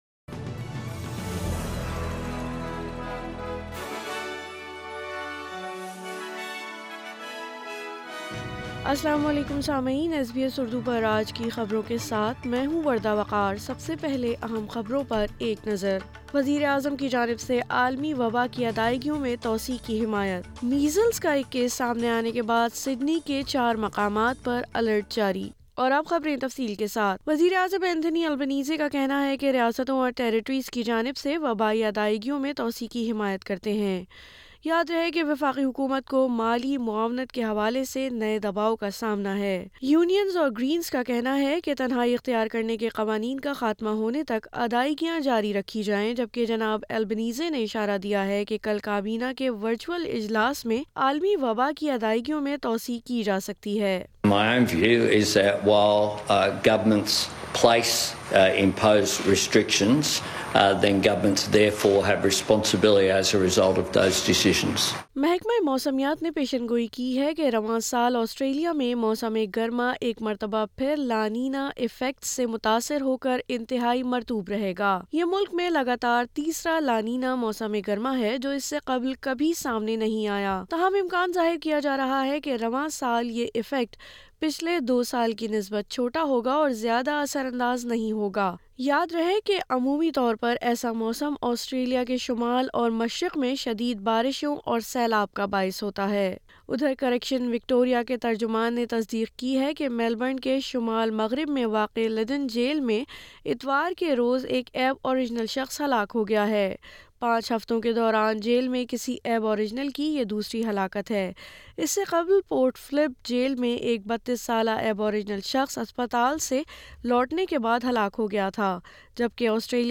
Urdu News 13 September 2022